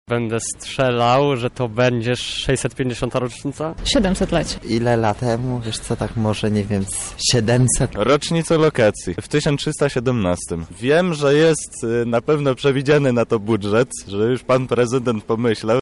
Mieszkańców Lublina zapytaliśmy czy wiedzą kiedy te wydarzenia miały miejsce: